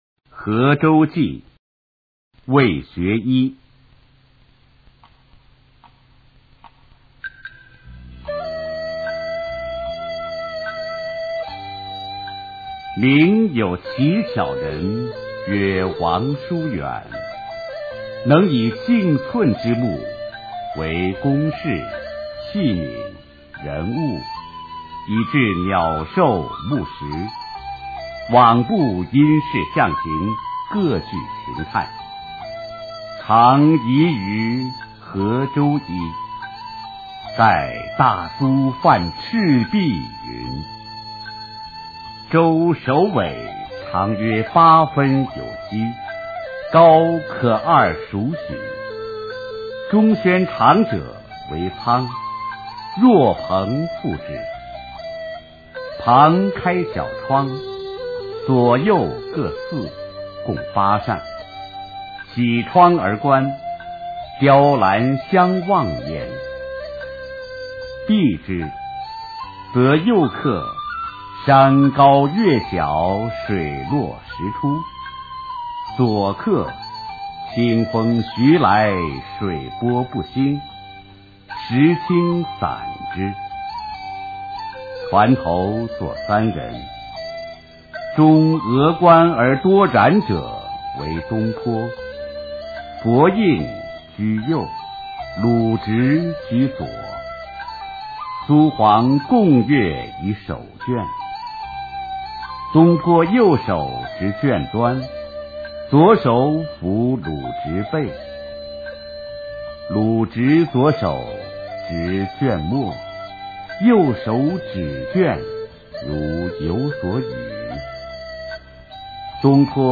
《核舟记》原文和译文（含在线朗读）　/ 魏学洢
语文教材文言诗文翻译与朗诵 初中语文八年级上册 目录